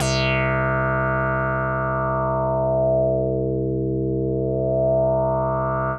C3_raspy_synth.wav